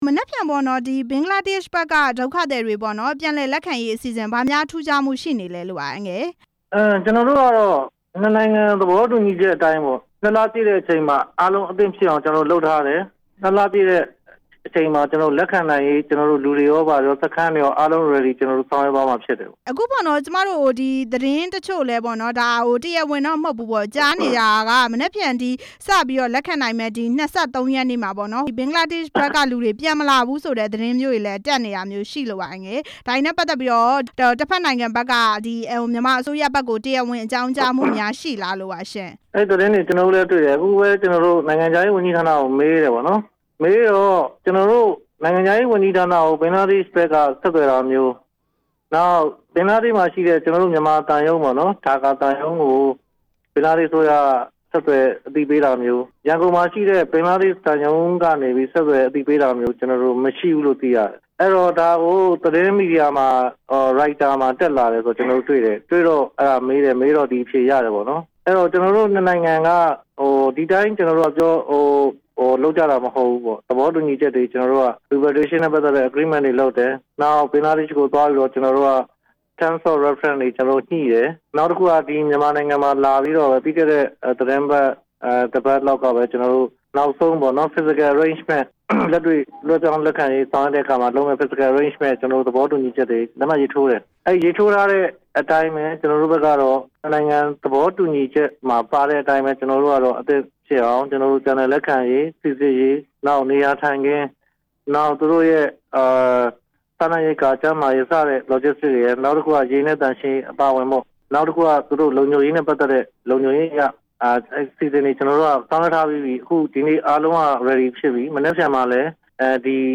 ဘင်္ဂလားဒေ့ရှ်နိုင်ငံက ဒုက္ခသည်တွေ ပြန်လည်လက်ခံရေးအကြောင်း ဦးဇော်ဌေးနဲ့ ဆက်သွယ်မေးမြန်းချက်